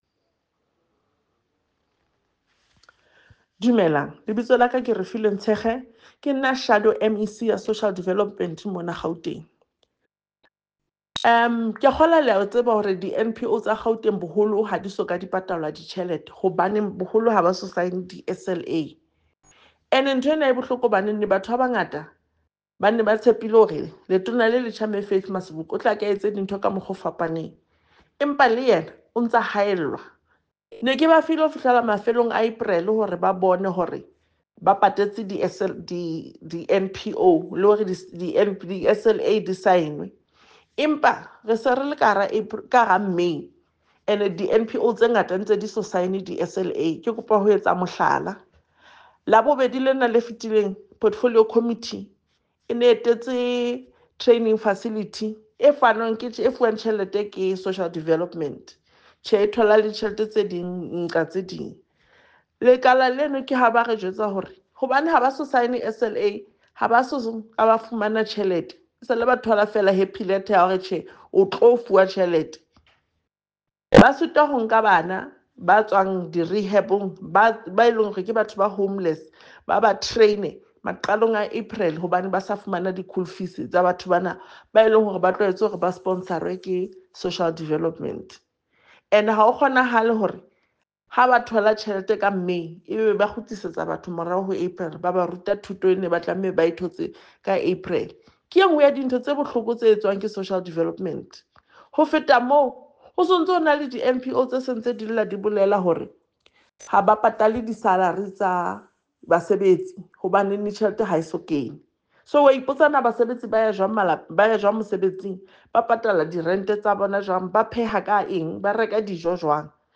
Sesotho soundbites by Refiloe Nt’sekhe MPL.